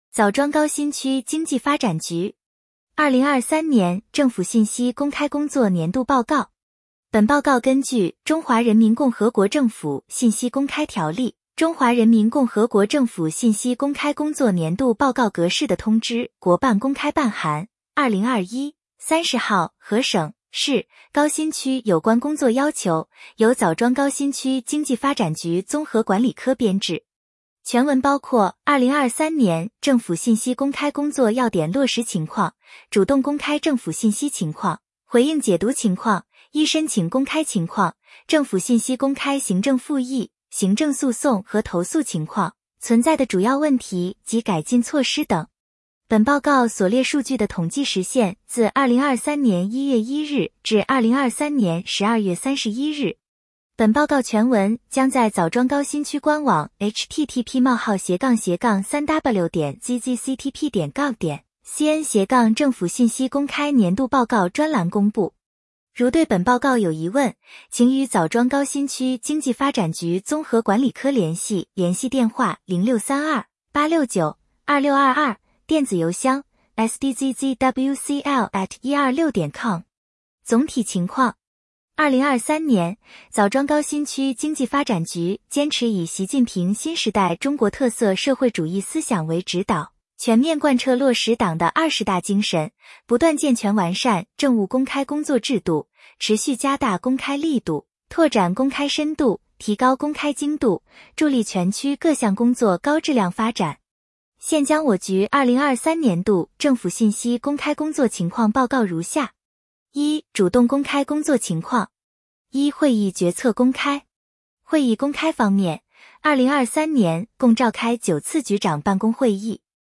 点击接收年报语音朗读 枣庄高新区经济发展局 2023年政府信息公开工作年度报告 作者：高新区经济发展局 来自：高新区经济发展局 时间：2024-01-26 本报告根据《中华人民共和国政府信息公开条例》《中华人民共和国政府信息公开工作年度报告格式》的通知（国办公开办函〔 2021 〕 30 号）和省、市 、高新区 有关工作要求，由枣庄高新区 经济发展局综合管理科 编制。